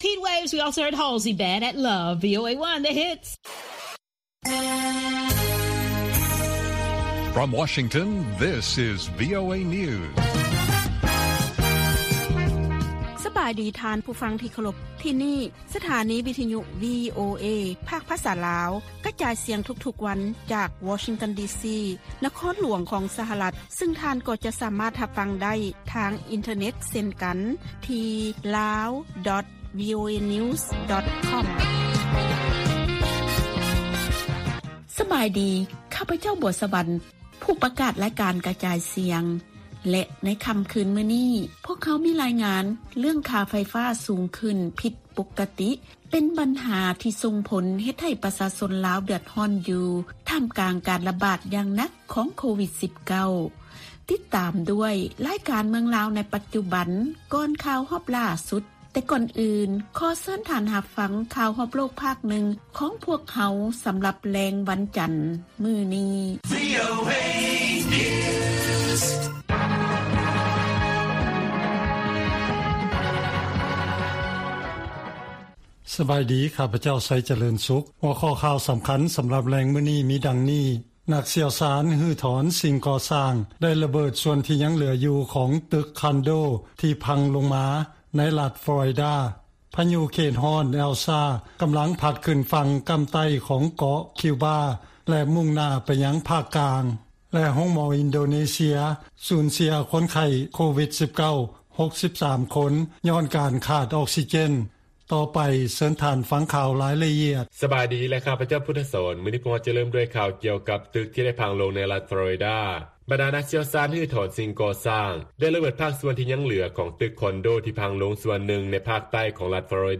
ລາຍການກະຈາຍສຽງຂອງວີໂອເອ ລາວ: ຄ່າໄຟຟ້າທີ່ສູງຂຶ້ນເປັນບັນຫາທີ່ສົ່ງຜົນໃຫ້ປະຊາຊົນເດືອດຮ້ອນ